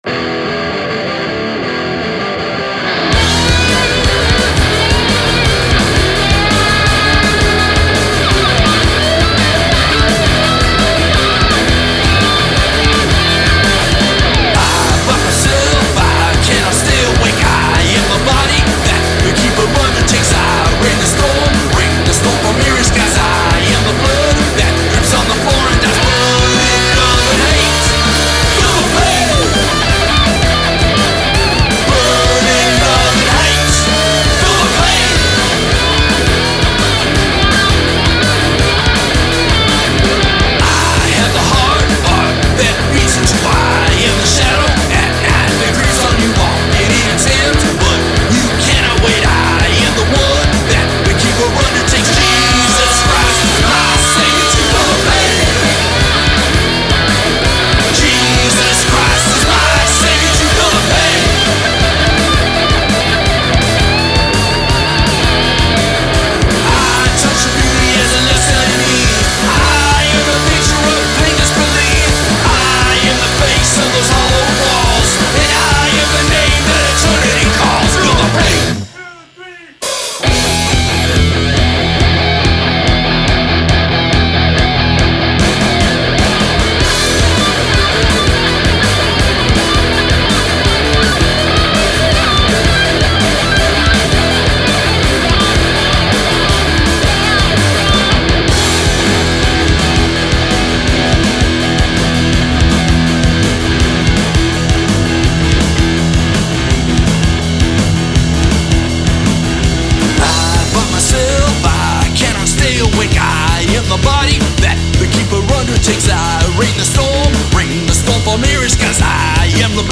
Recorded at Mole Tracks West